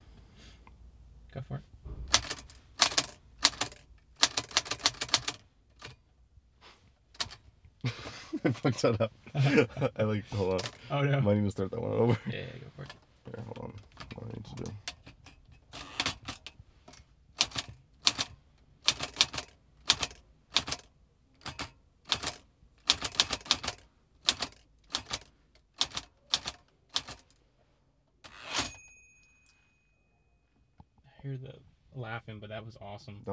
TypewriterClicks
click ding typewriter sound effect free sound royalty free Sound Effects